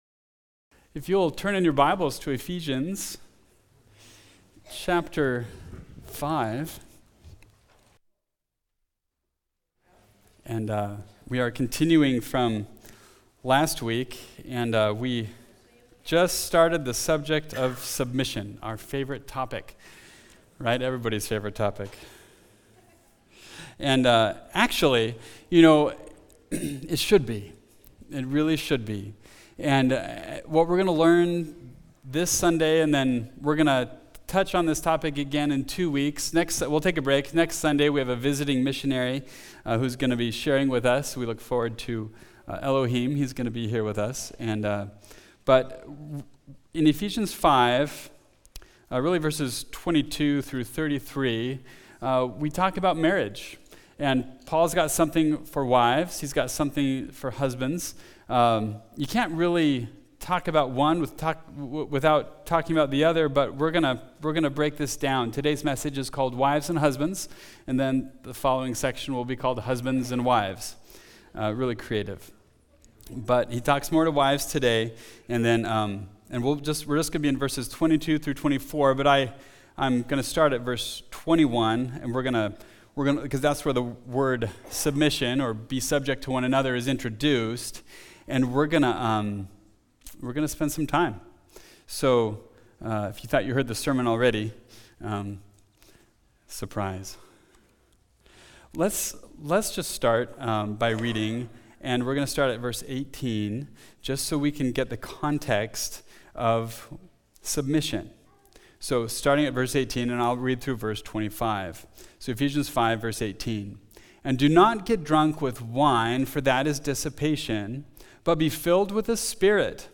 Wives and Husbands – Mountain View Baptist Church